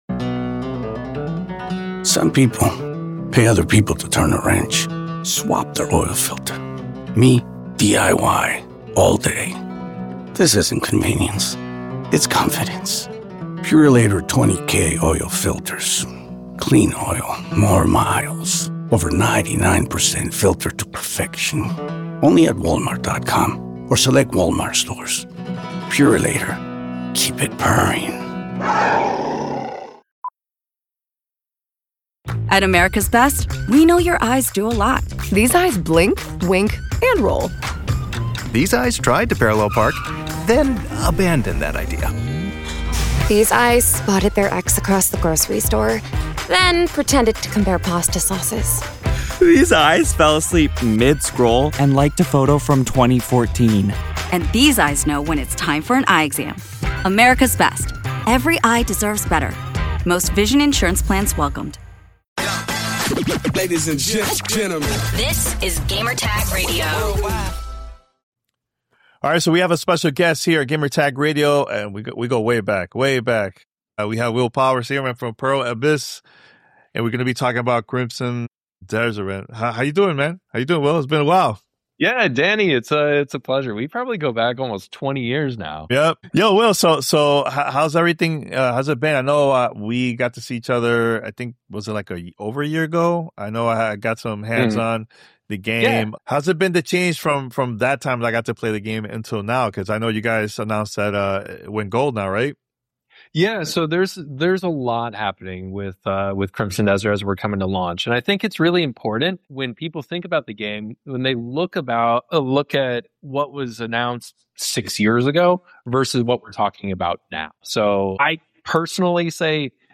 Crimson Desert interview with Pearl Abyss